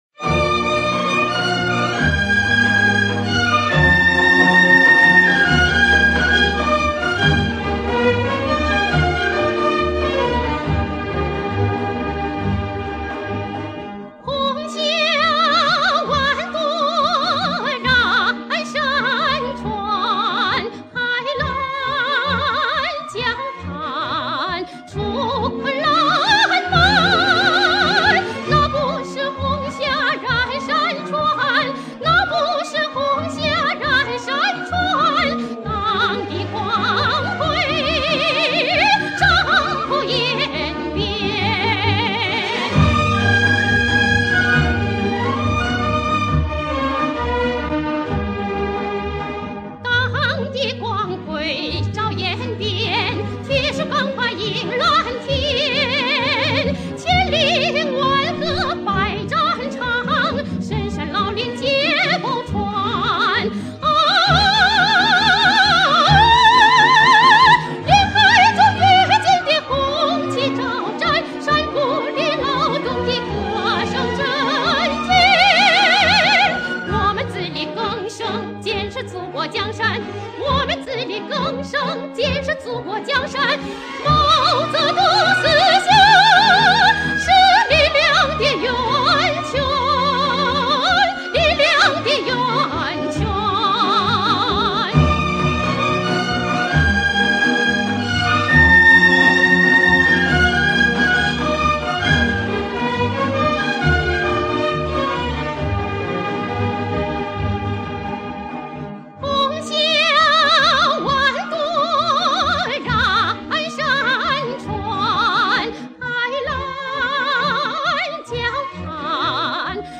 是一首具有浓郁的朝鲜族风情的歌曲
曲调慷慨激昂，歌词大气磅礴